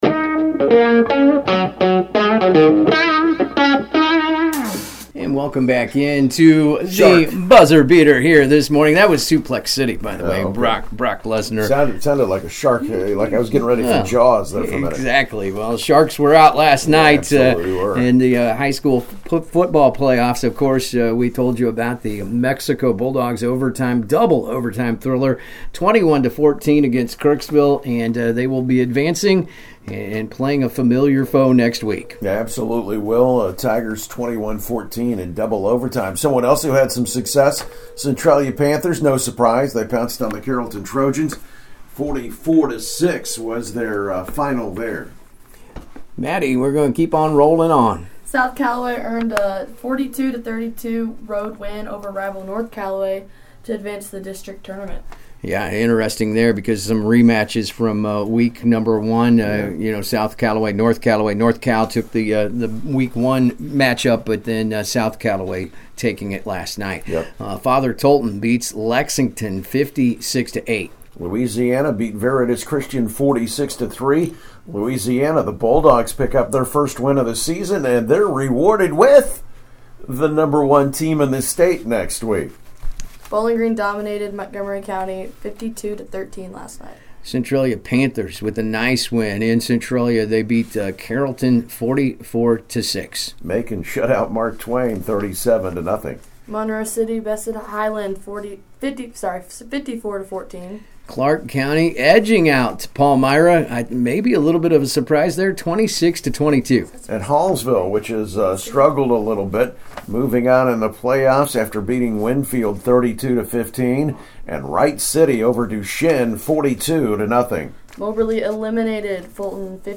Mexico Boys Basketball Talks Upcoming Season On AM 1340 KXEO’s Local Sports Talk Show The Buzzer Beater